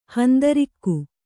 ♪ handarikku